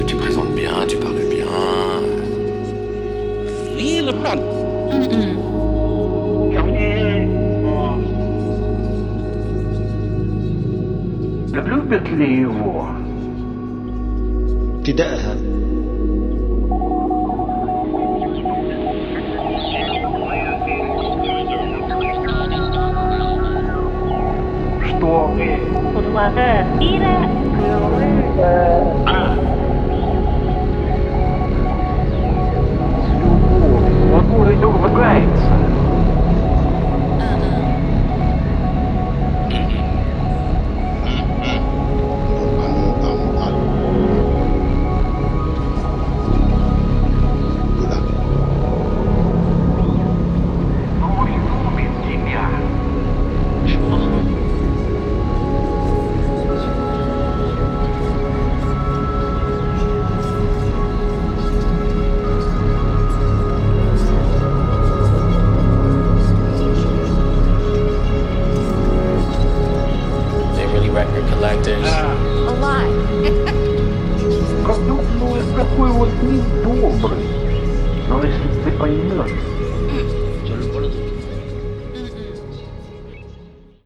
Dutch experimental music